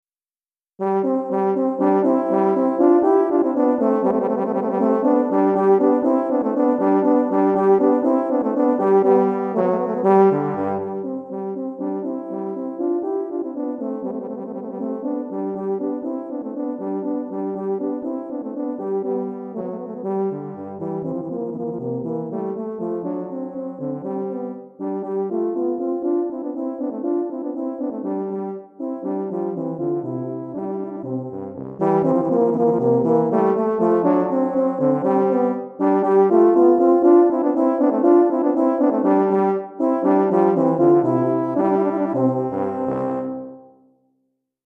Recueil pour Tuba, euphonium ou saxhorn - 2 Tubas